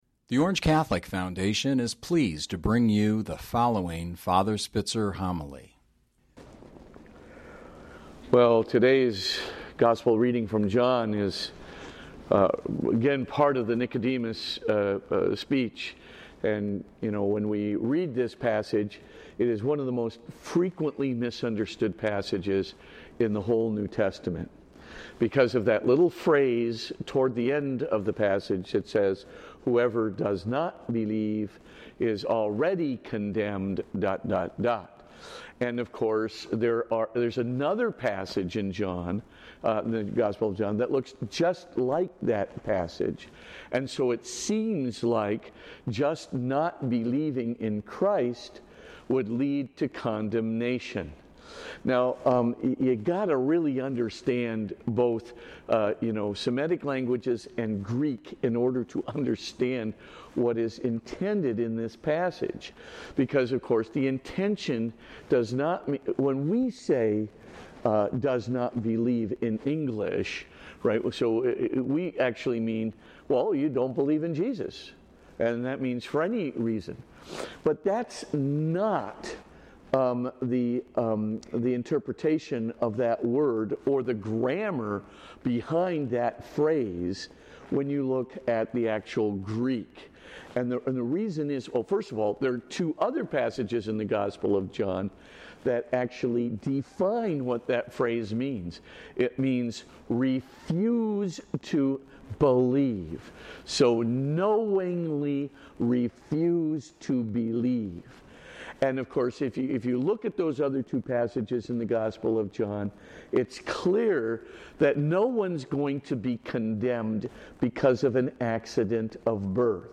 Podcast (fr_spitzer_homilies): Play in new window | Download